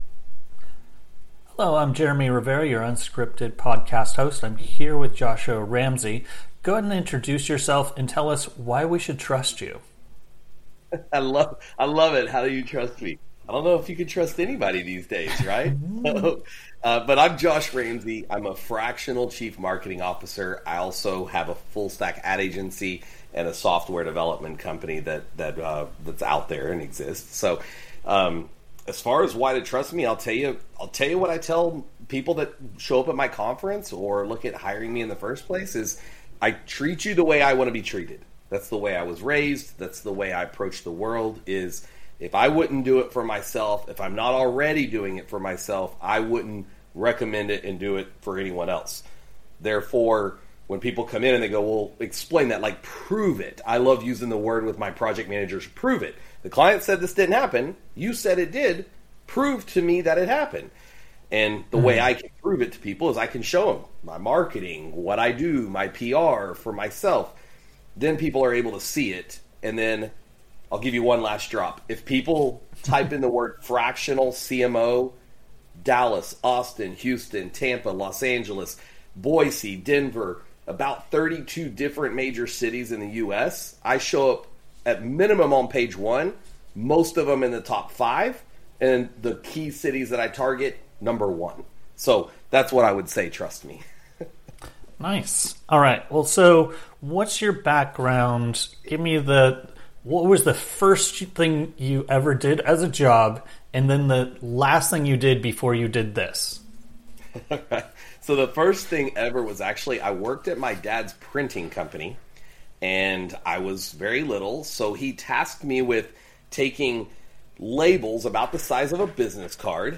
SEO Interview Podcasts